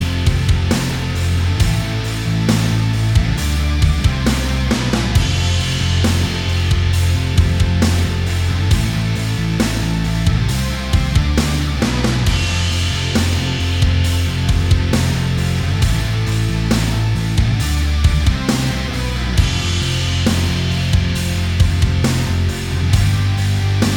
Minus All Guitars For Guitarists 4:38 Buy £1.50